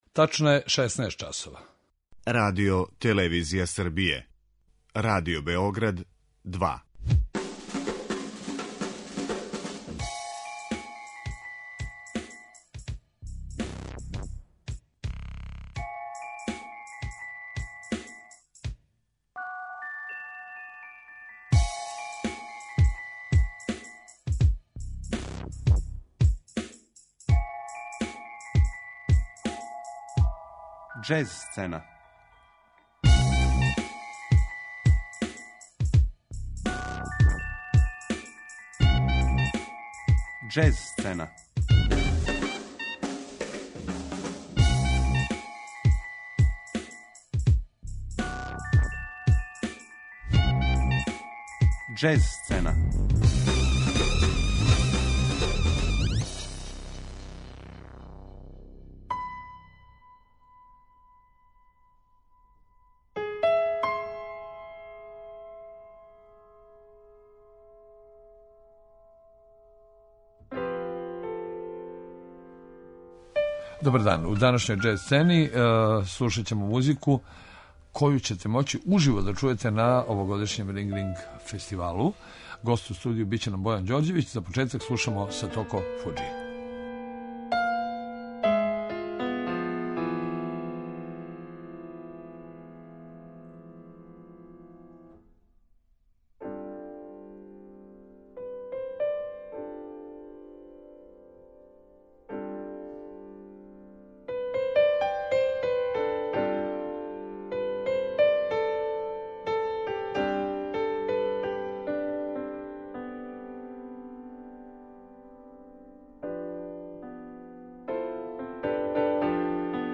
У другом делу емисије слушамо музику норвешких џез уметника који су наступали на овогодишњем сајму „Jazzahead" у Бремену.